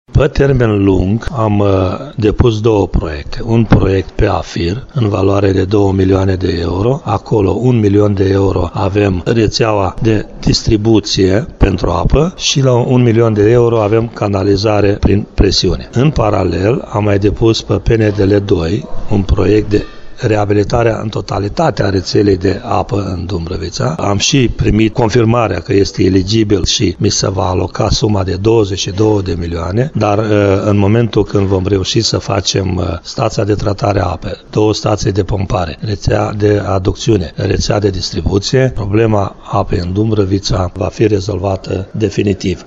Primarul din Dumbrăvița, Victor Malac, spune că problema o constituie faptul că rețeaua este subdimensionată.